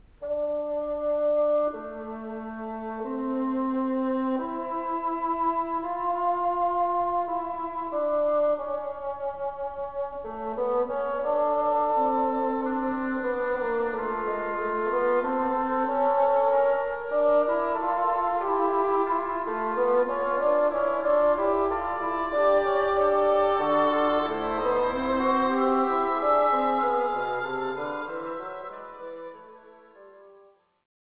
dc.contributor.otherErich Bergel (cond.) ; Cluj Philharmonic Orchestrahu